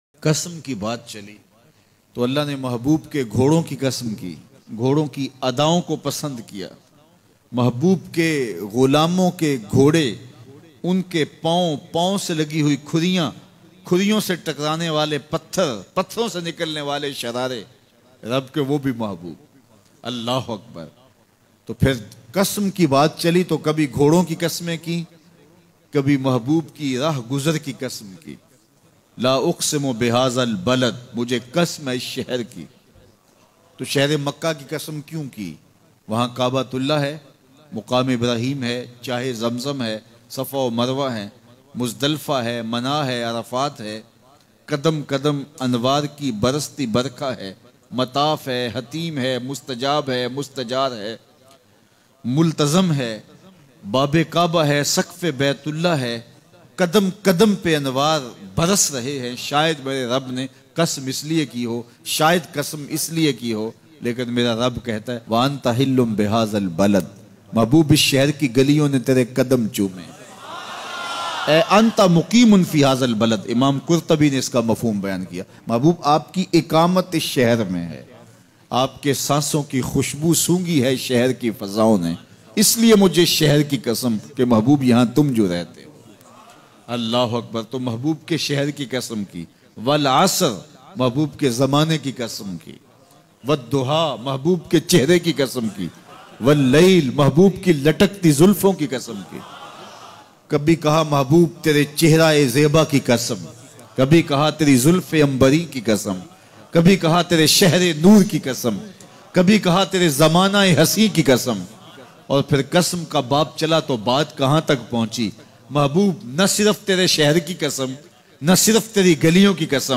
New Bayan 2018 mp3